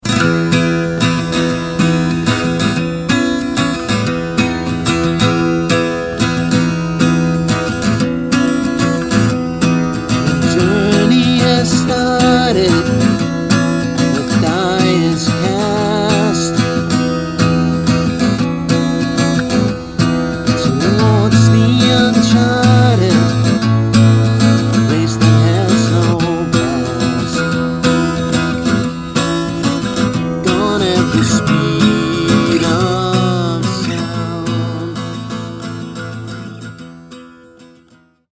• Indie
• Pop
• Rock
• Rock and roll
Guitar
Trommer
Vokal